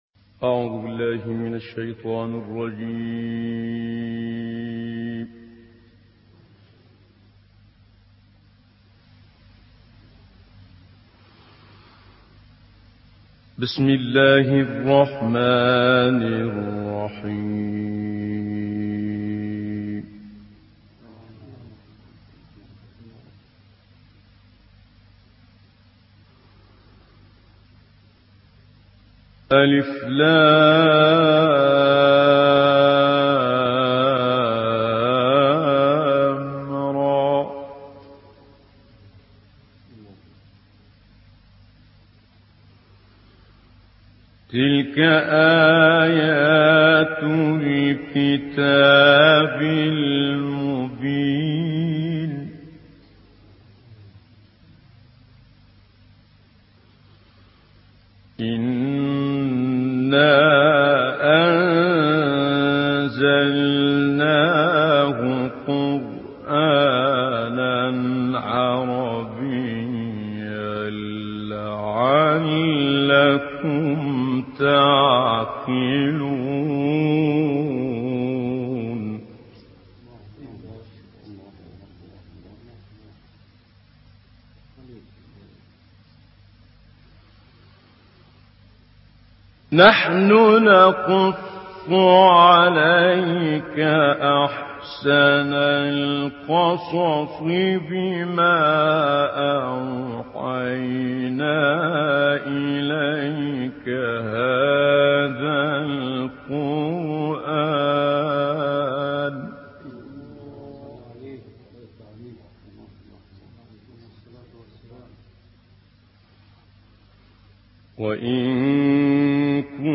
تلاوت آیاتی از سوره یوسف توسط استاد متولی عبدالعال
تلاوت قرآن کريم